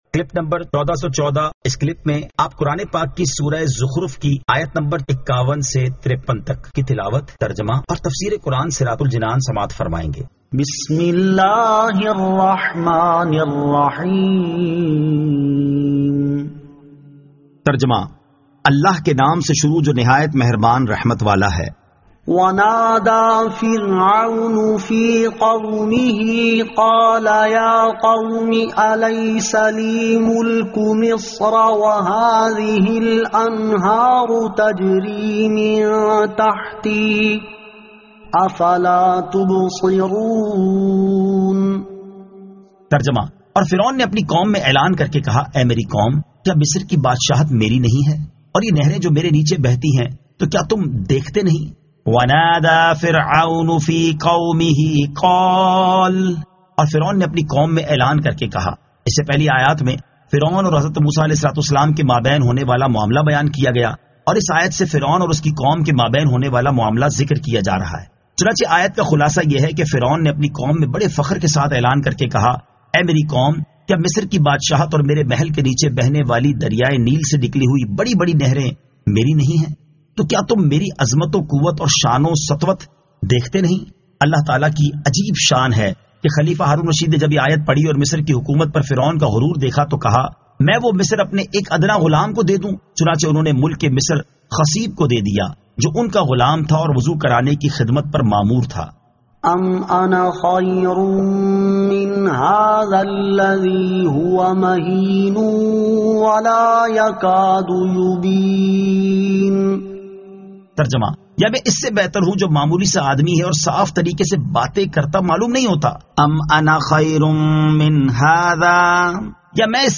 Surah Az-Zukhruf 51 To 53 Tilawat , Tarjama , Tafseer